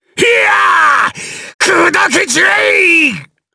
Phillop-Vox_Skill4_jp.wav